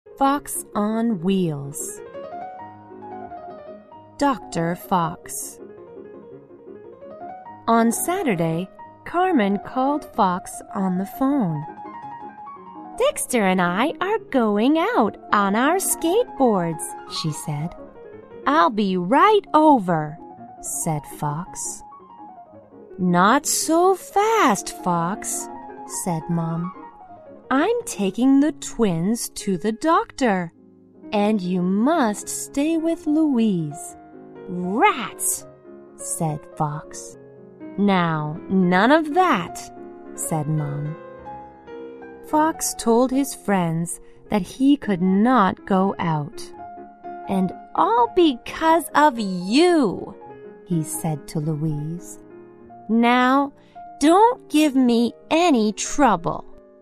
在线英语听力室小狐外传 第42期:风火轮的听力文件下载,《小狐外传》是双语有声读物下面的子栏目，非常适合英语学习爱好者进行细心品读。故事内容讲述了一个小男生在学校、家庭里的各种角色转换以及生活中的趣事。